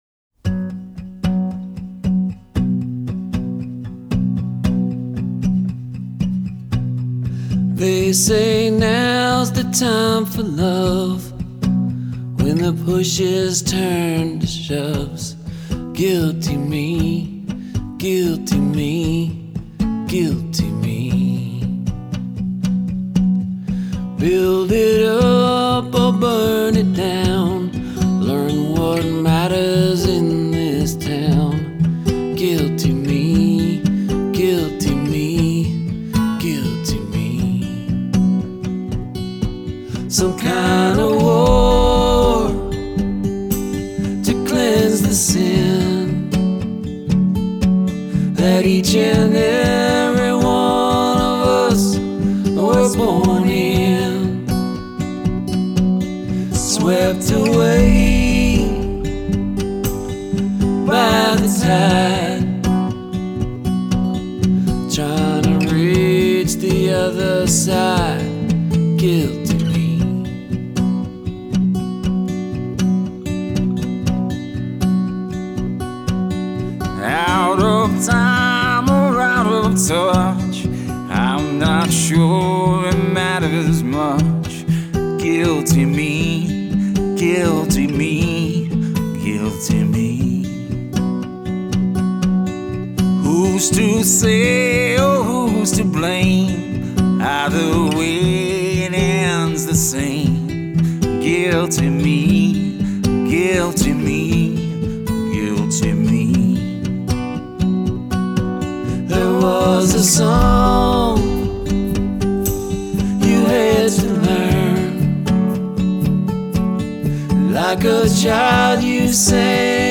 Vocals, guitars
recorded in quarantine from our separate bunkers